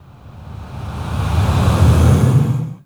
SIGHS 3REV-R.wav